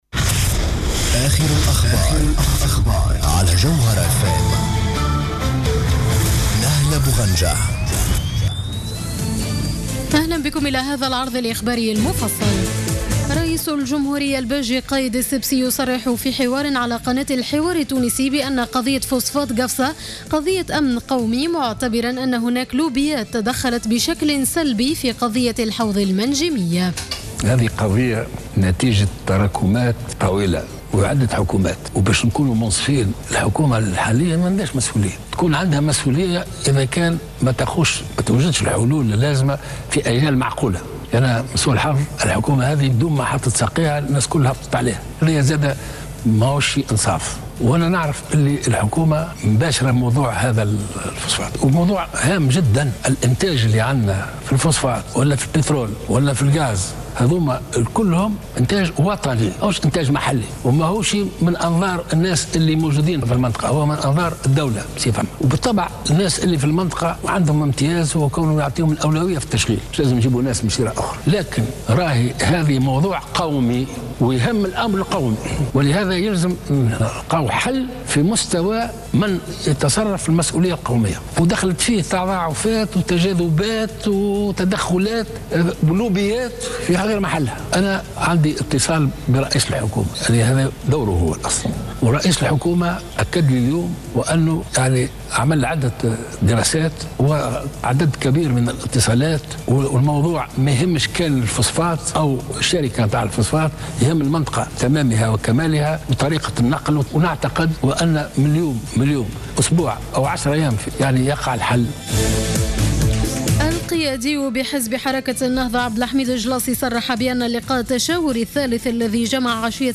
نشرة أخبار منتصف الليل ليوم السبت 9 ماي 2015